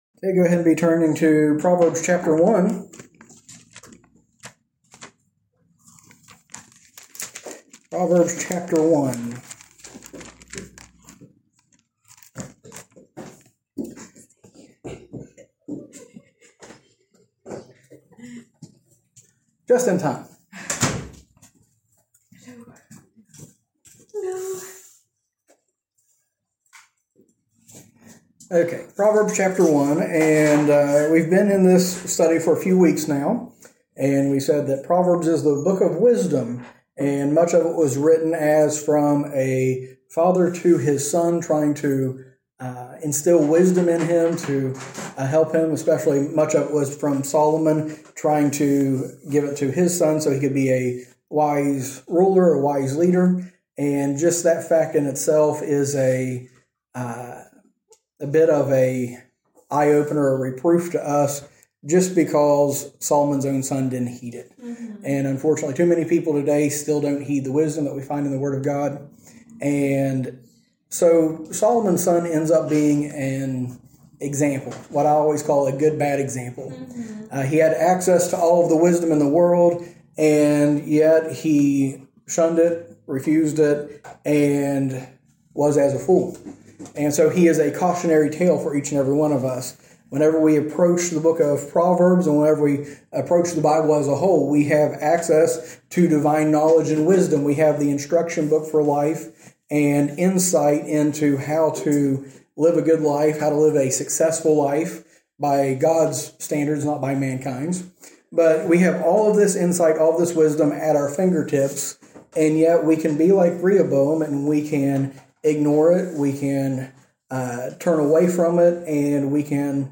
Message
A message from the series "Proverbs."